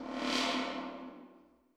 59LOOP SD3-R.wav